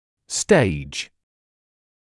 [steɪʤ][стэйдж]стадия, эпат (часто о лечении)